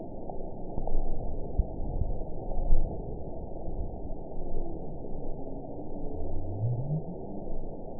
event 921791 date 12/19/24 time 02:22:40 GMT (11 months, 2 weeks ago) score 9.53 location TSS-AB03 detected by nrw target species NRW annotations +NRW Spectrogram: Frequency (kHz) vs. Time (s) audio not available .wav